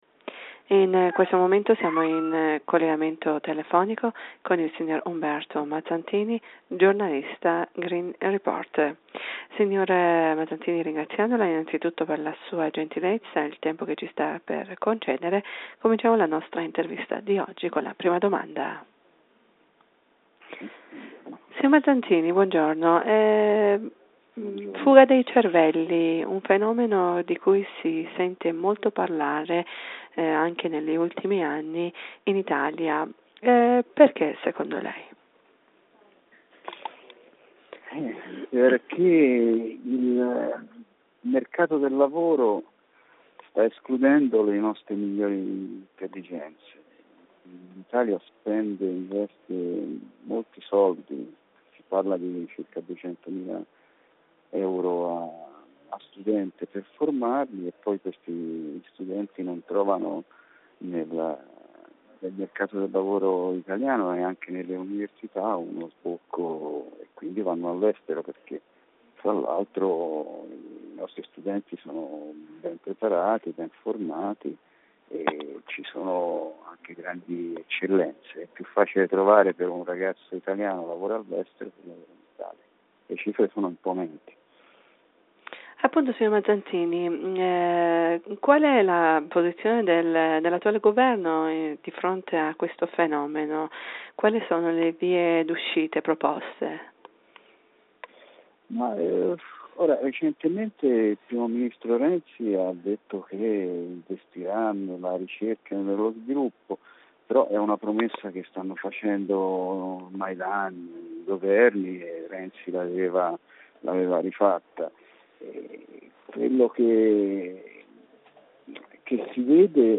in un'intervista telefonica a Radio Italia